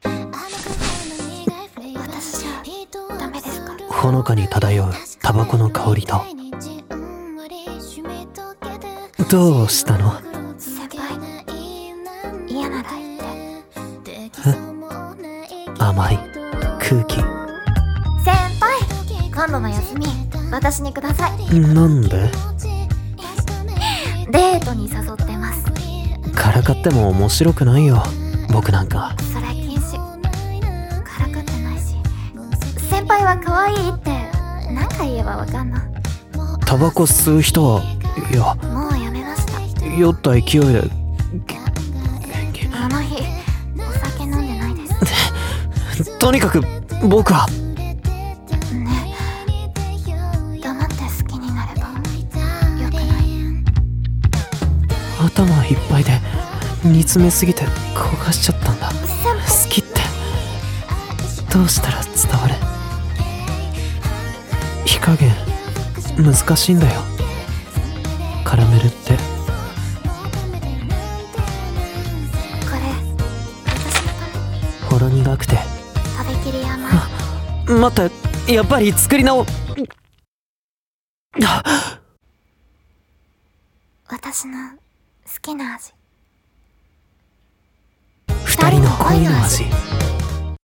声劇】こがれる、きゃらめりぜ。